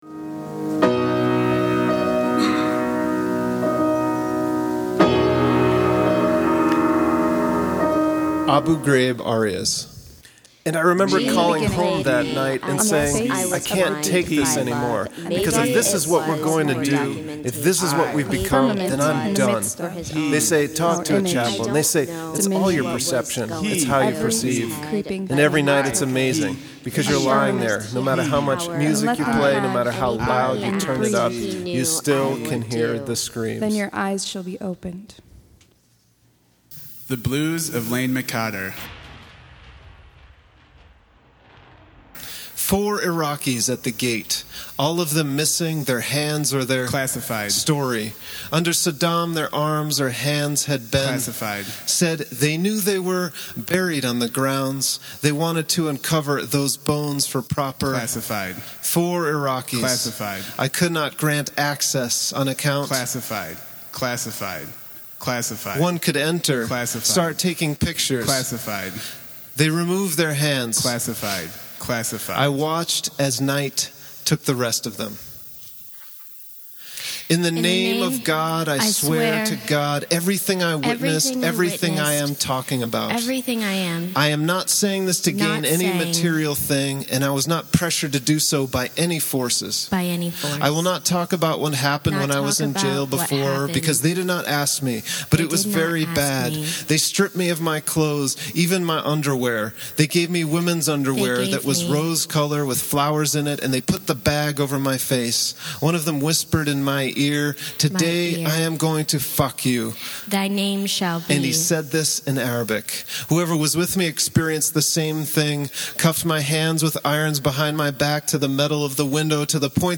A reading of abu ghraib arias